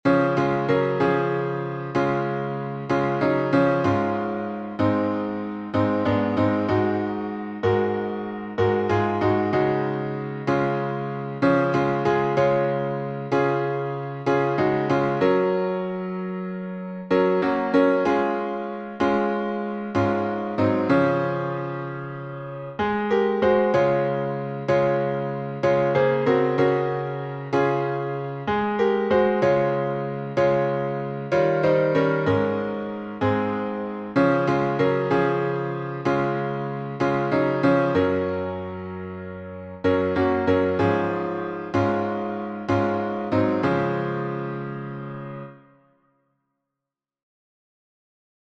Once for All — D flat major.